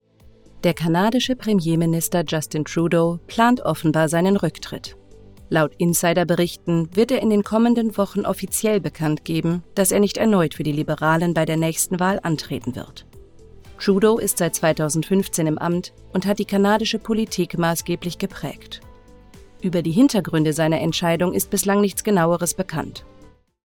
Sprecherin
Nachrichten, Demo